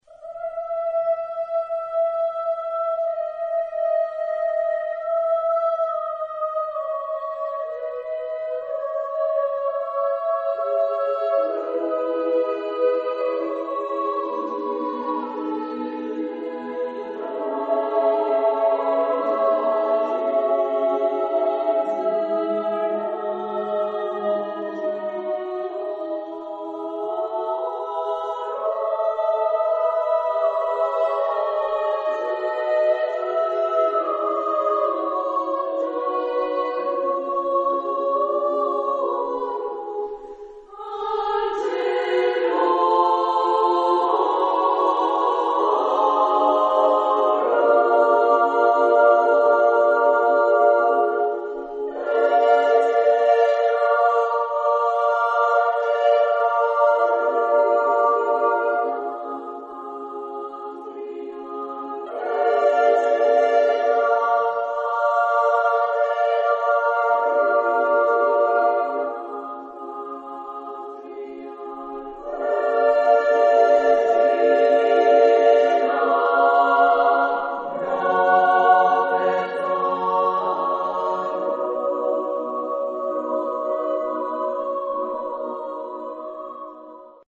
Genre-Style-Forme : Sacré ; Prière
Caractère de la pièce : spirituel ; majestueux ; lent
Type de choeur : SSA  (3 voix égales de femmes )
Tonalité : mi majeur